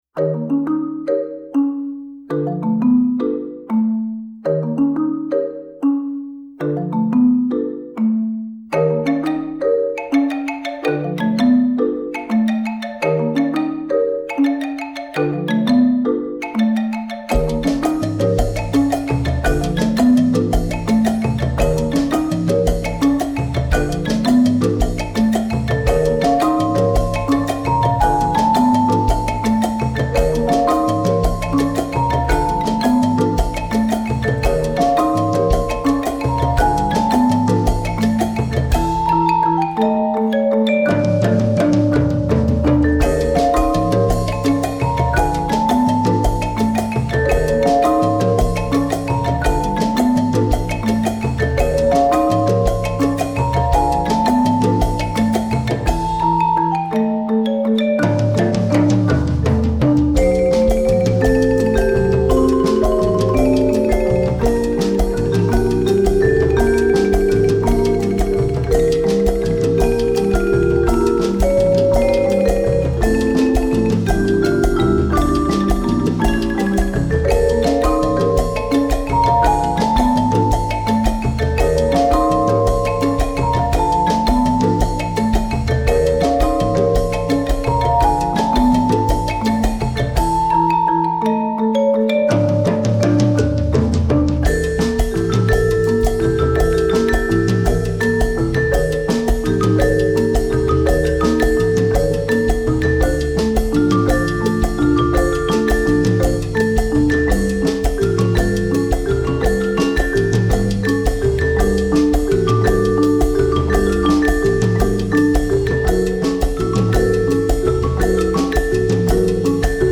Instrumentalnoten für Schlagzeug/Percussion